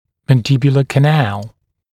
[ˌmæn’dɪbjulə kə’næl][ˌмэн’дибйулэ кэ’нэл]нижнечелюстной канал